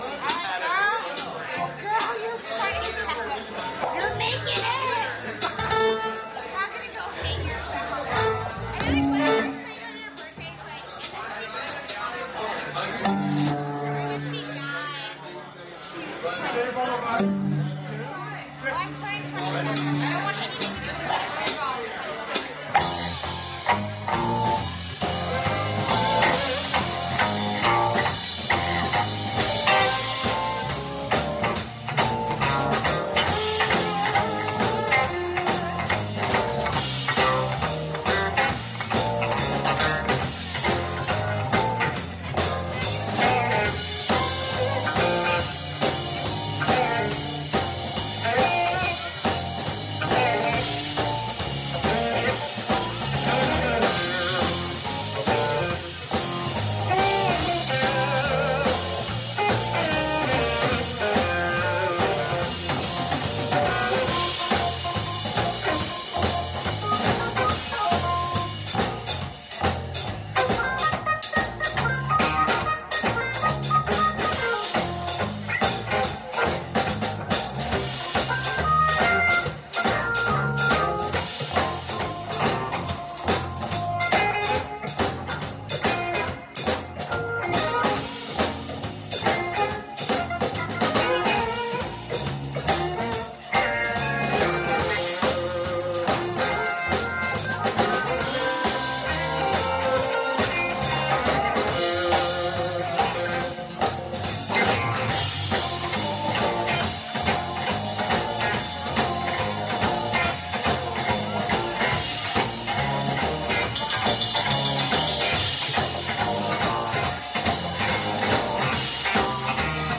guitar
saxophone
organ
violin
bass
special guest vocalist